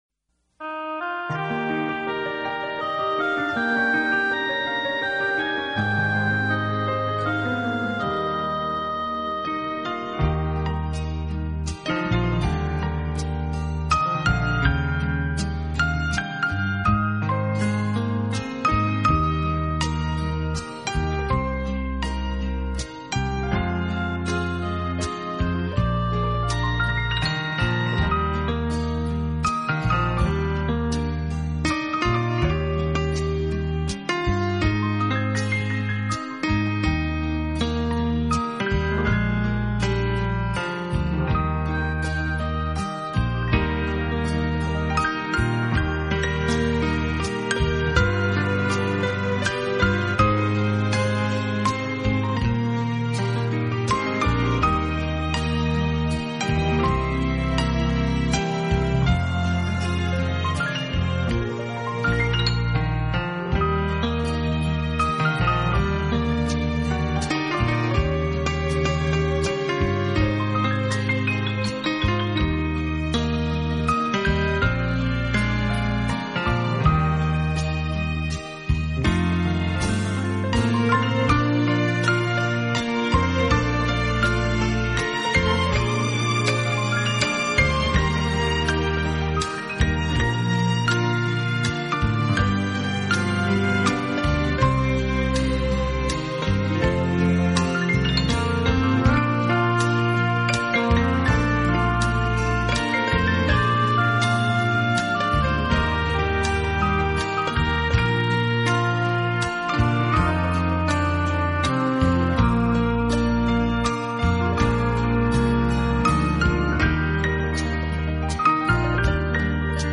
【浪漫钢琴】
这是一套非常经典的老曲目经过改编用钢琴重新演绎的系列专辑。
本套CD全部钢琴演奏，